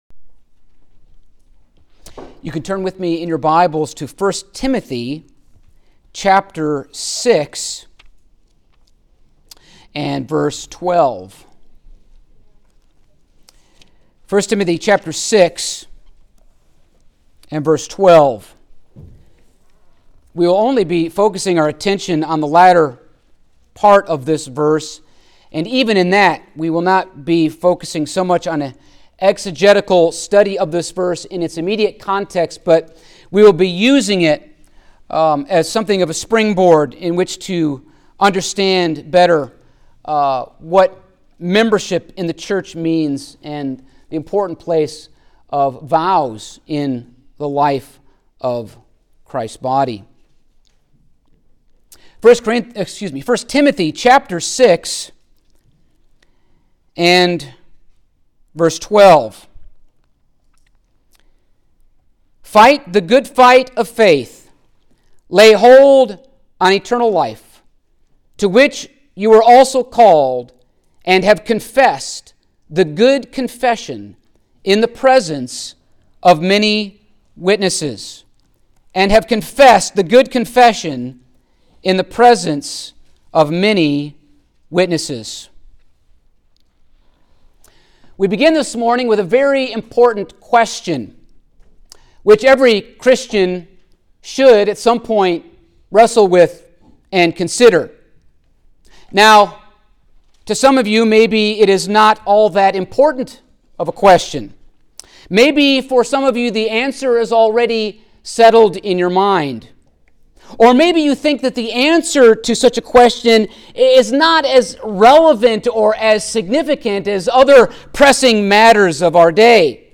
Stand Alone Sermons Passage: 1 Timothy 6:12 Service Type: Sunday Morning Topics: Presbyterian Church Government « Jesus Washing the Disciples’ Feet